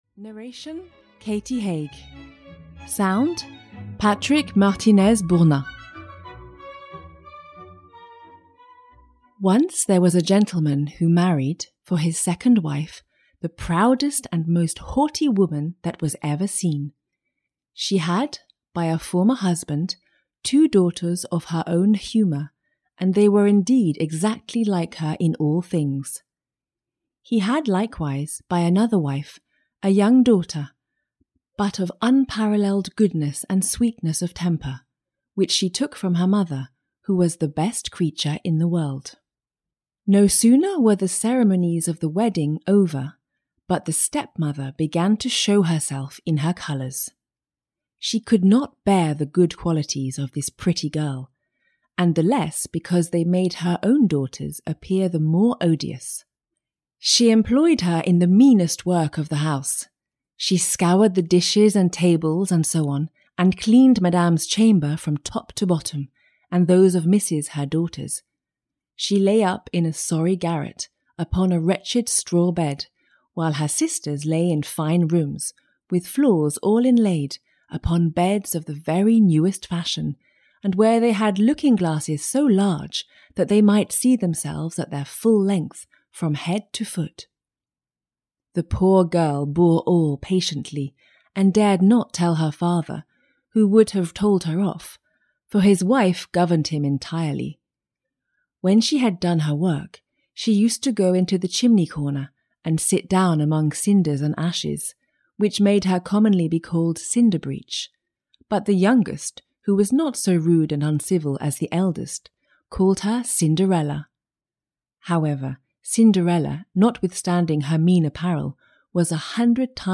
Ukázka z knihy
These stories include: Cinderella by Charles Perrault, Beauty and the Beast, Blue Beard, Snow White and Rose Red, Snowdrop and the Seven Dwarves, The Fairies, The Master Cat or Puss in Boots, The Sleeping Beauty in the Woods, The Frog Prince, The Princess and the Pea by Hans Christian Andersen, The Toad, and Rapunzel. All are narrated in a warm and lively way for the best of enjoyment.